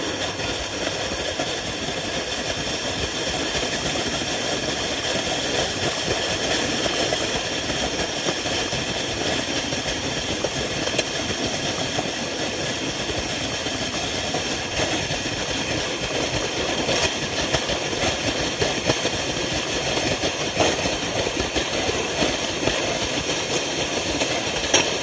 描述：孩子们从右到左在雪地上“滑雪”下坡。录制于瑞典斯德哥尔摩的Vitabergsparken，配有Zoom H6及其MSH6麦克风。
标签： 场记录 子女 父母 雪橇 体育 冬季 下坡
声道立体声